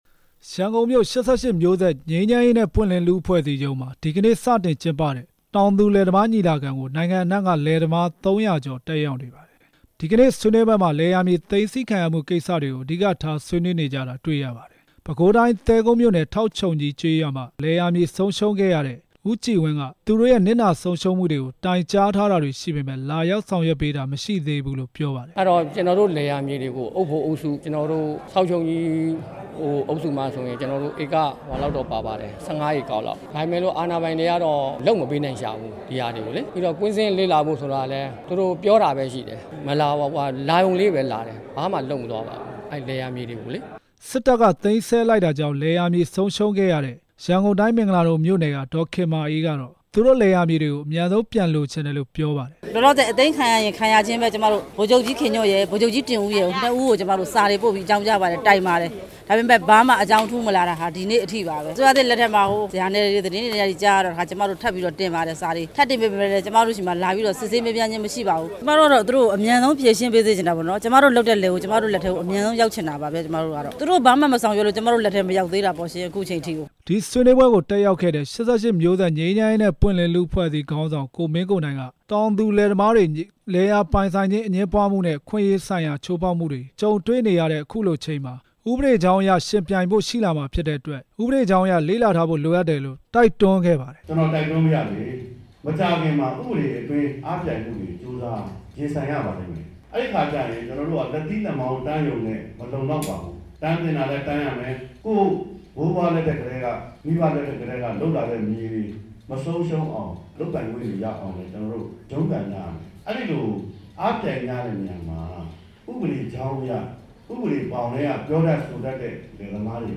တောင်သူလယ်သမား ညီလာခံ တင်ပြချက်